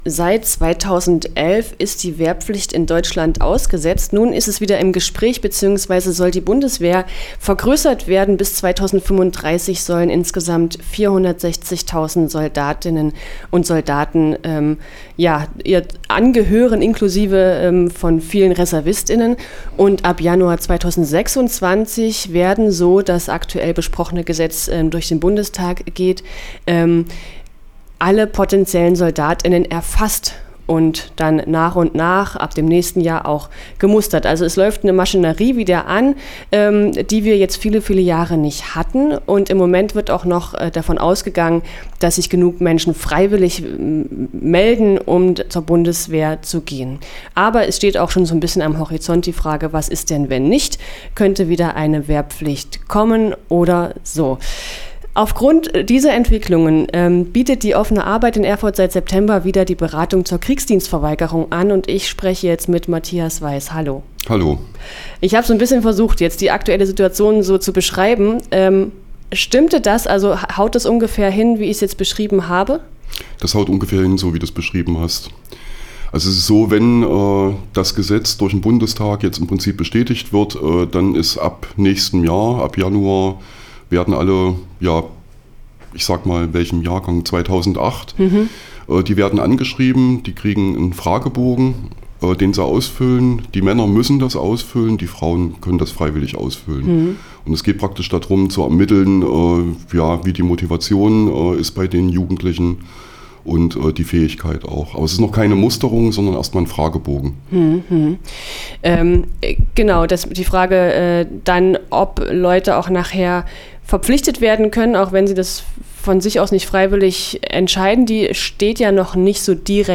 Beratung zur Kriegsdienstverweigerung | Interview